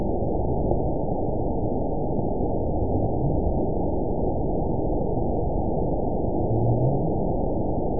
event 917336 date 03/28/23 time 04:56:07 GMT (2 years, 1 month ago) score 9.54 location TSS-AB01 detected by nrw target species NRW annotations +NRW Spectrogram: Frequency (kHz) vs. Time (s) audio not available .wav